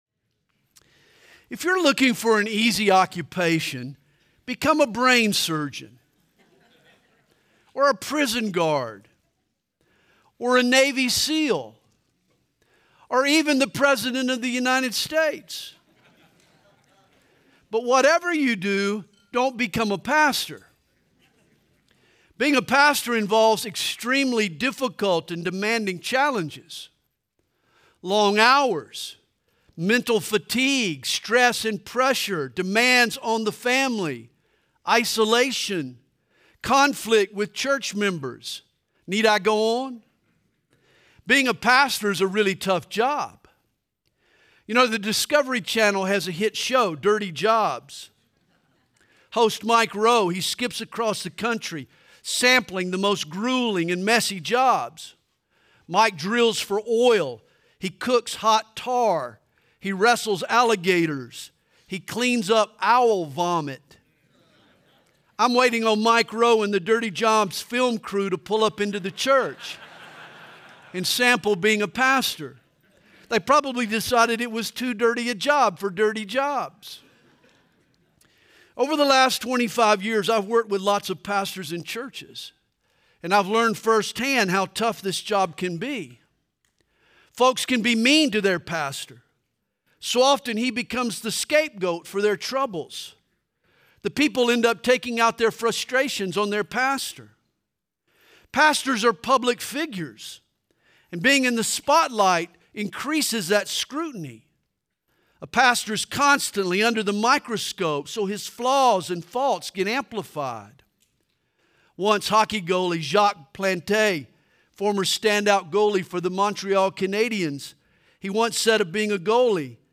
2025 DSPC Conference: Pastors & Leaders Date